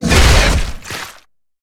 Sfx_creature_squidshark_bitelong_01.ogg